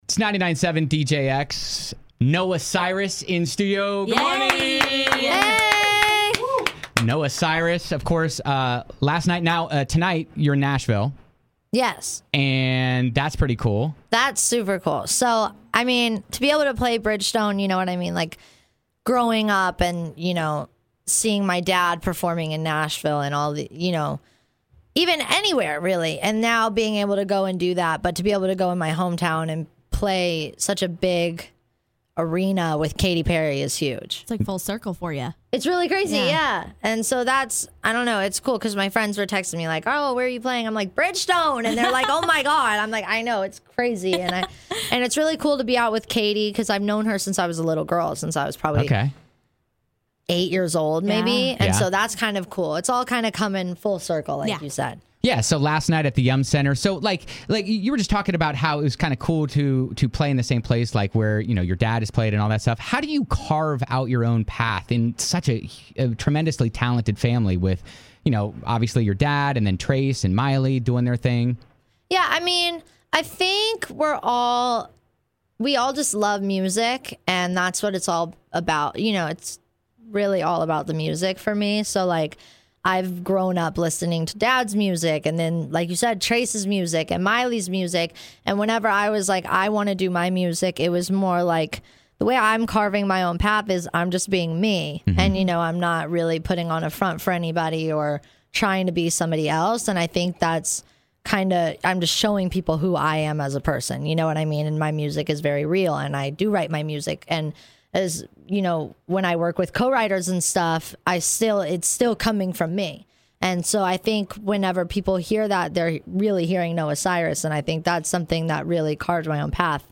Noah Cyrus opened up for Katy Perry in Louisville. Before she went on stage, she stopped by 99-7 DJX with her dad, Billy Ray to talk about growing up in the Cyrus family, her music, and Billy Ray describing what it's like being a dad in all this.